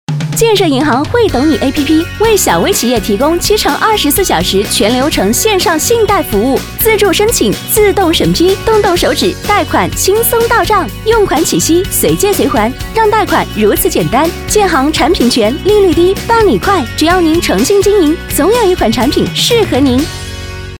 女13-【电台包装】建设银行惠懂你APP
女13旁白宣传片配音 v13
女13--电台包装-建设银行惠懂你APP.mp3